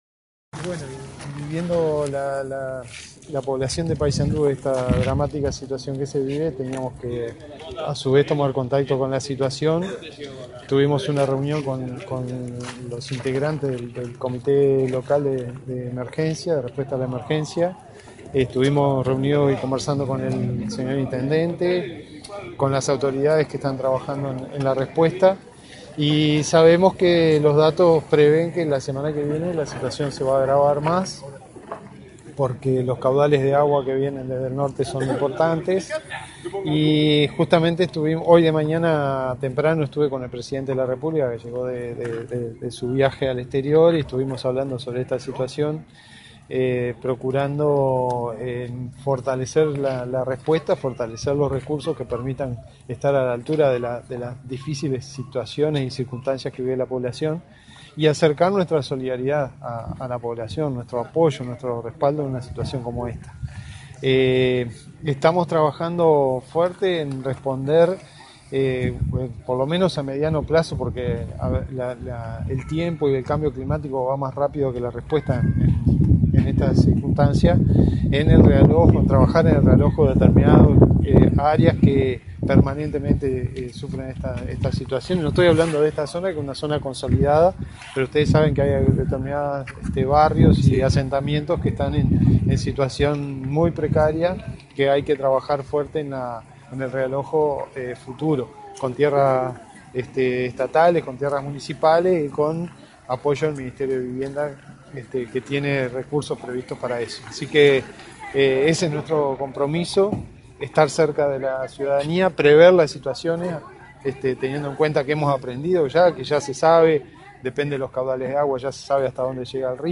El vicepresidente de la República, Raúl Sendic, recorrió las zonas inundadas de Paysandú donde se reunió con vecinos y autoridades locales. En ese marco, comentó a la prensa que informó al presidente Vázquez de la situación tras su arribo al país y acotó que el Gobierno está trabajando en el realojo de familias que viven en zonas inundables con apoyo del ministerio de Vivienda que tiene recursos previstos para ello.